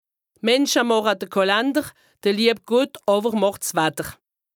Haut Rhin
Ville Prononciation 68
2APRESTA_OLCA_LEXIQUE_METEO_AIR_HAUT_RHIN_417_0.mp3